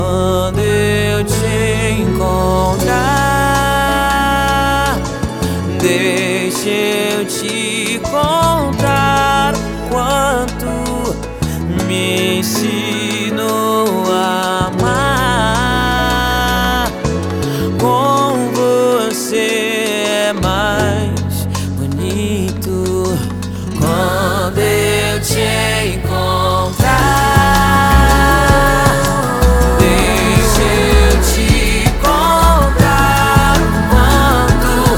Brazilian Pop
Жанр: Поп музыка